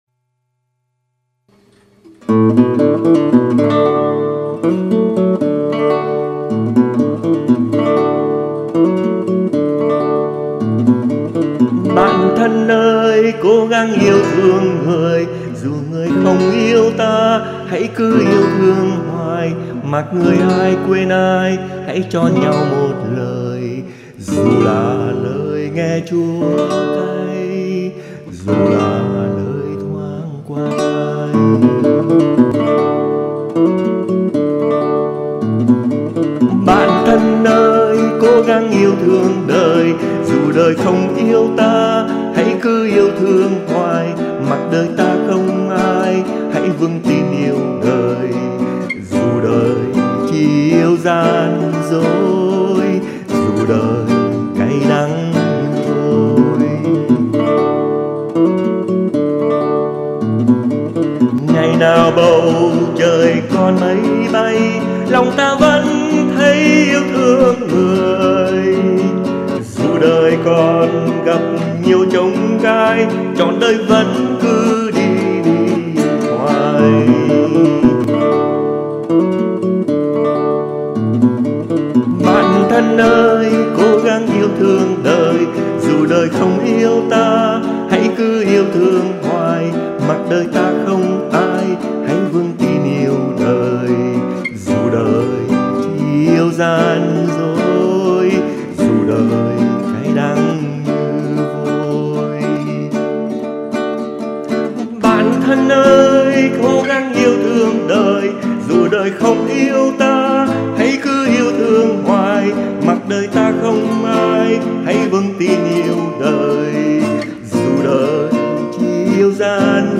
Liên khúc
đàn và hát